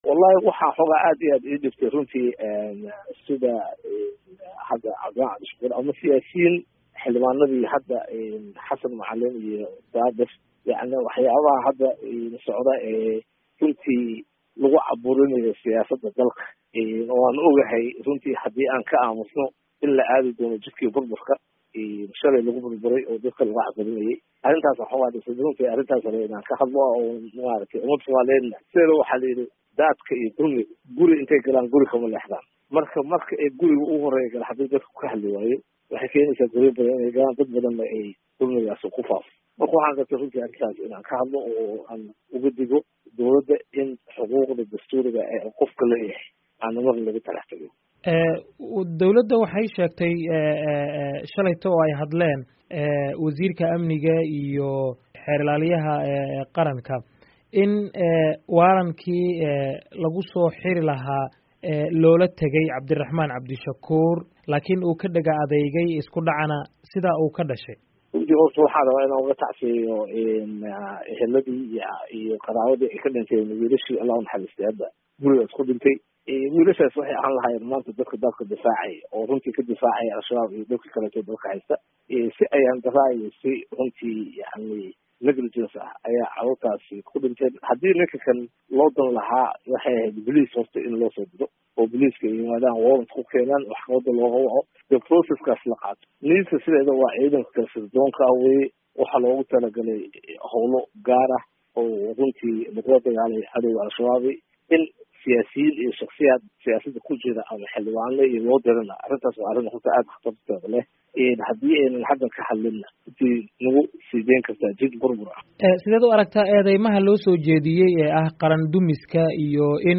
Halkaan ka dhageyso wareysiga Cumar Cabdirashid